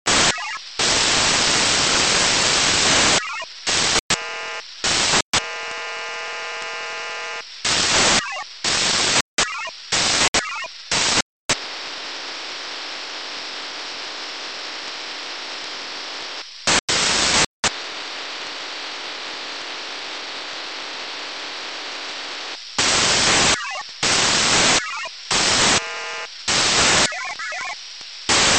File:VHF Winlink audio.mp3 - Signal Identification Wiki
VHF_Winlink_audio.mp3